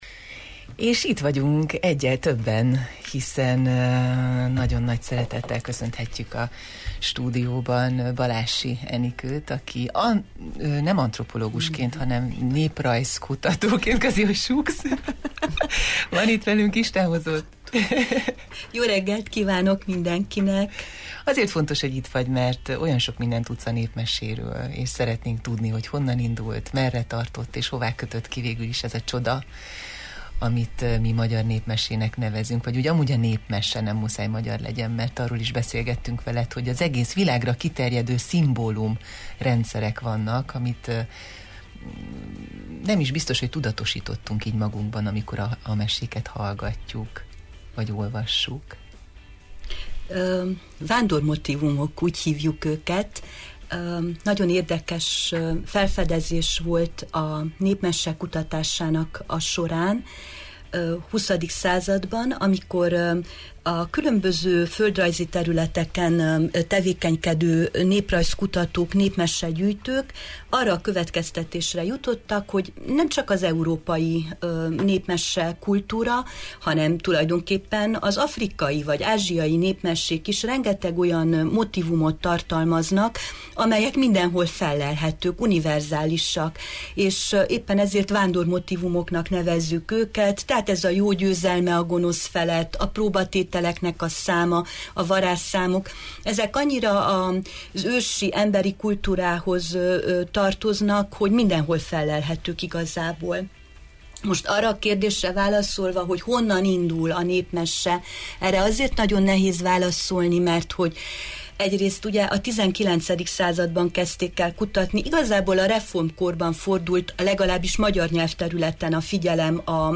magyartanárral beszélgettünk a Jó reggelt, Erdély!-ben